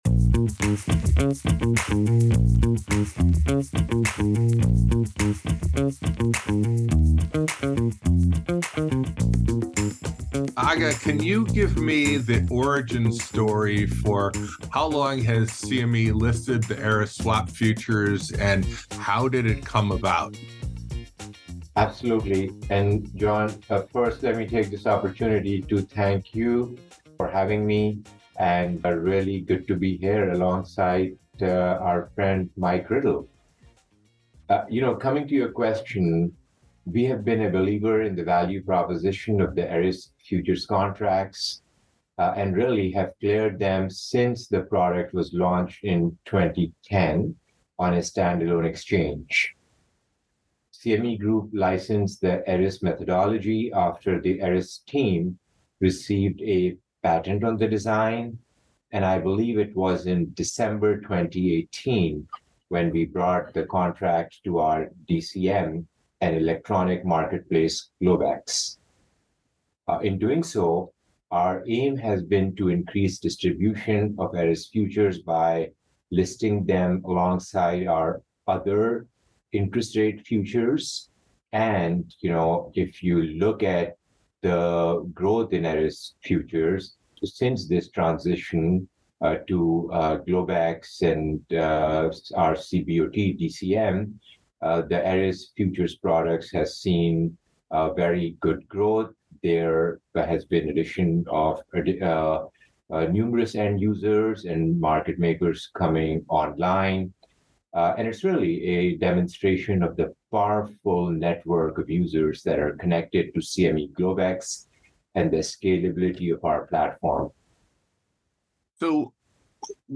Eris-Interview-mixdown.mp3